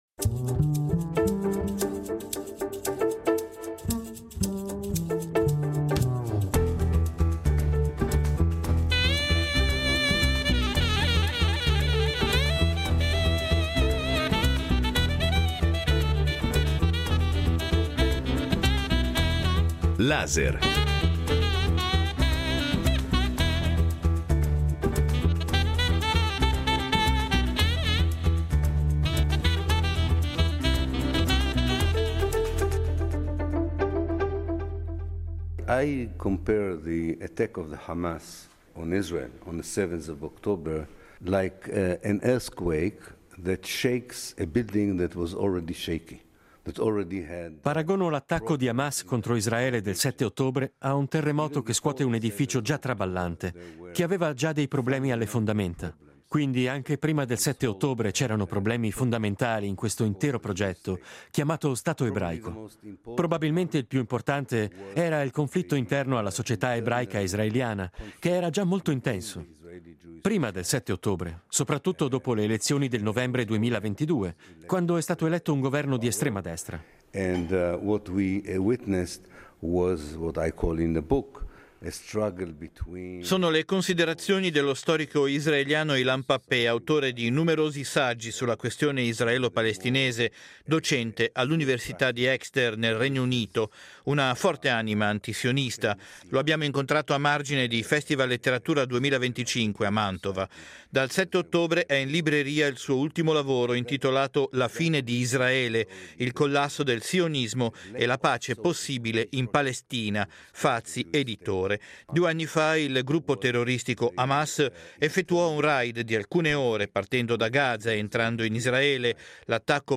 Colloquio con lo storico israeliano Ilan Pappé (2./2)